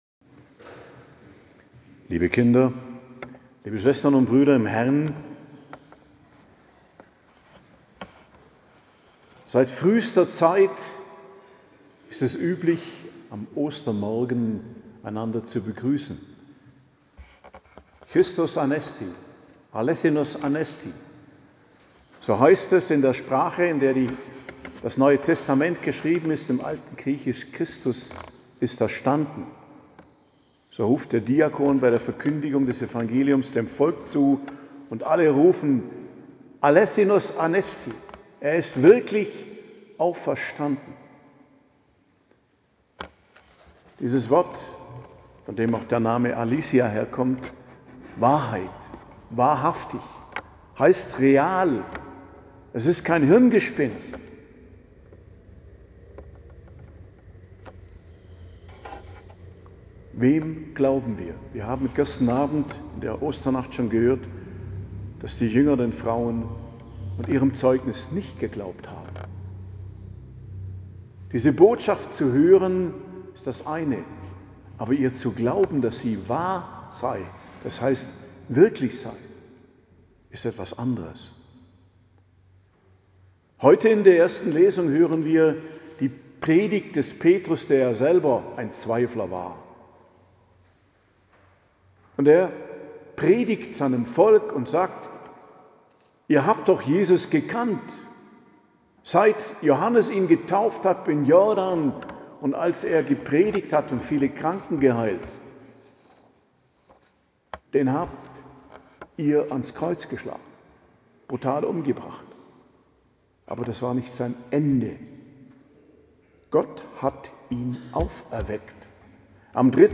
Predigt zum Ostersonntag, 20.04.2025 ~ Geistliches Zentrum Kloster Heiligkreuztal Podcast